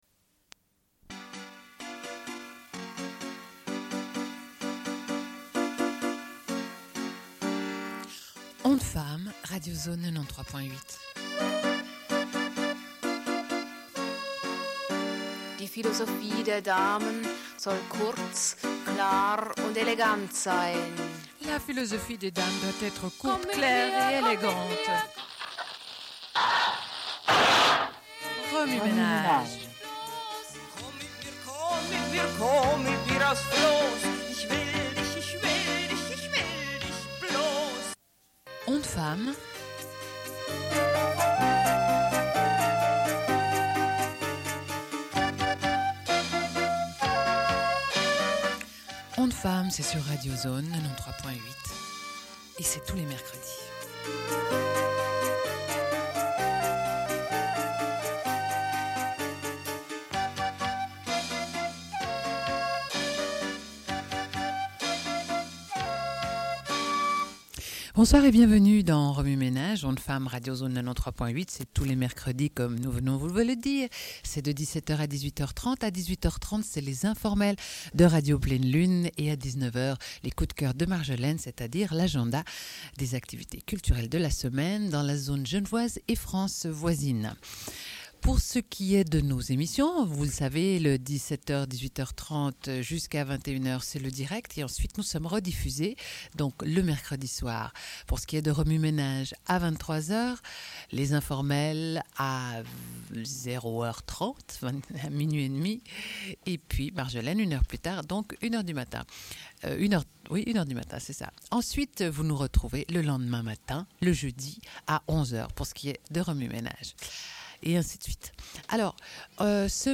Sommaire de l'émission : chronique « Voix humaine », musique ou lecture. Cette semaine, émission musicale.
Une cassette audio, face A